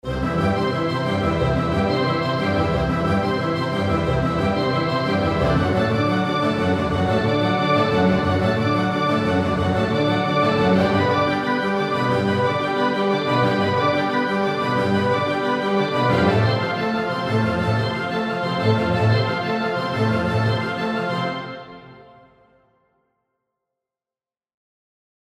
Damit lasse ich das Signal rund um den Hörer wandern. Es hört sich nun so an, als ob Sie sich um die eigene Achse drehen würden: